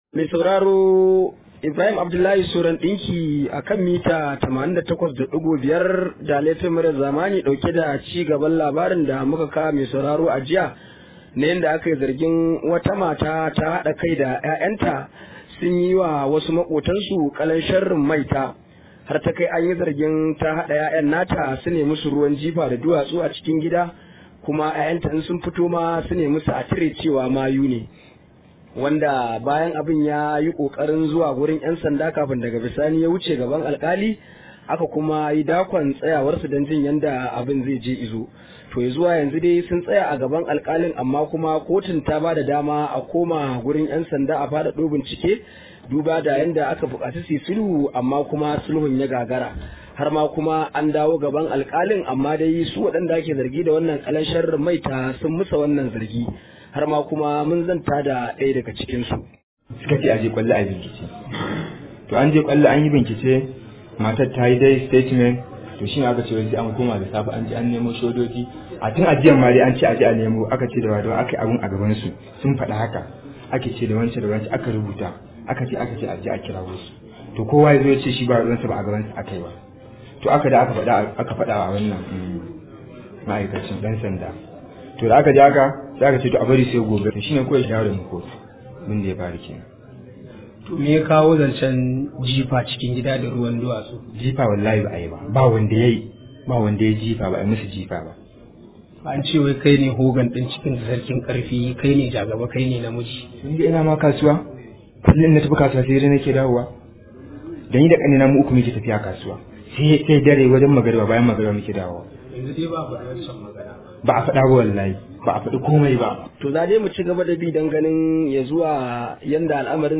Akwai cikakken rahoton a muryar da ke kasa.